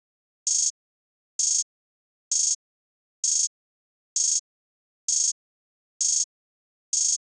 HiHat (36).wav